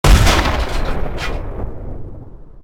Tank_Turret1.ogg